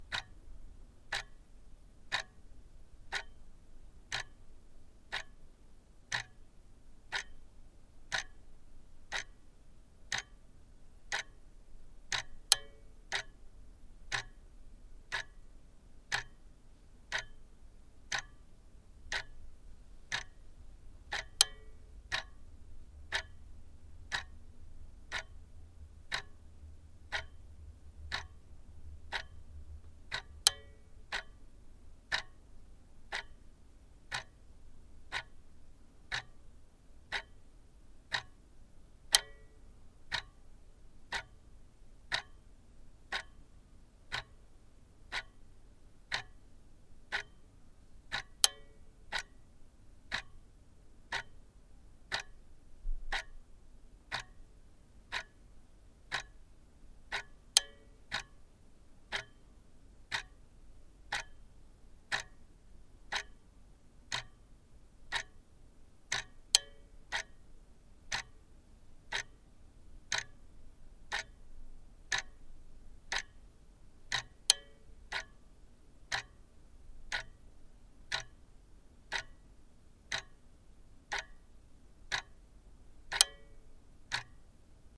机械设备 " 时钟 ddr elmech ruhla quartz 01nr
用Sennheiser MKH80和Nagra LB录制。 降噪应用。
Tag: 挂钟 蜱滴答 RELOJ 时间 滴答 发条 时钟